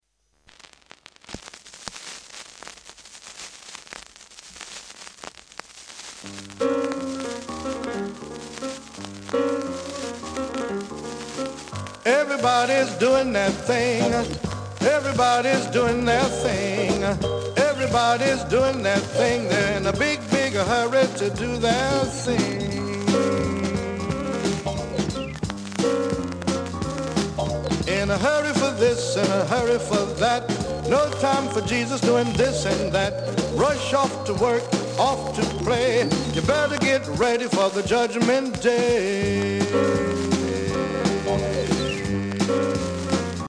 Label water damaged both sides, plays with crackles.